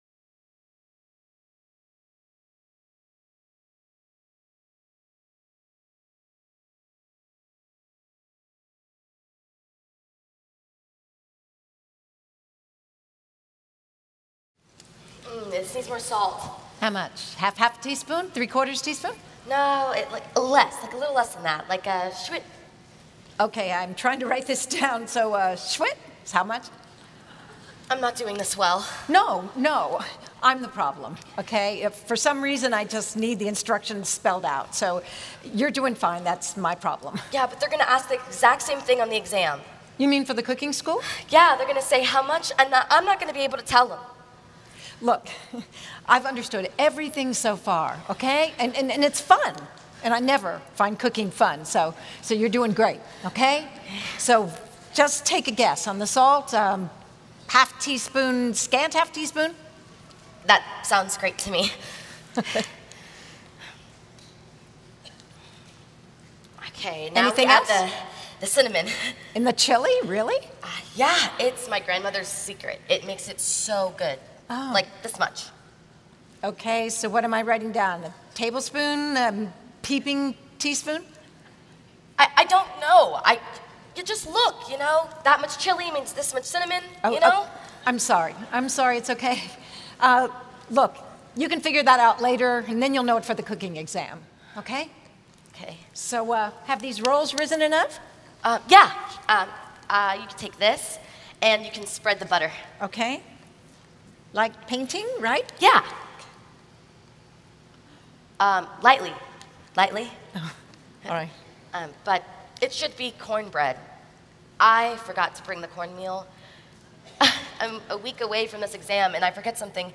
Message 5 (Drama): The Cooking Lesson